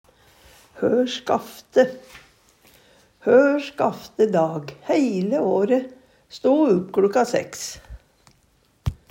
hør skafte - Numedalsmål (en-US)